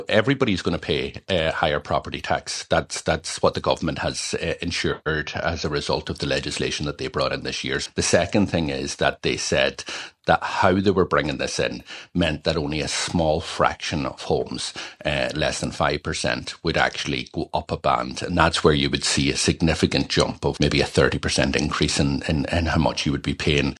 Donegal TD and Sinn Féin Finance Spokesperson Pearse Doherty says the government has misled people about how much more they’ll pay………..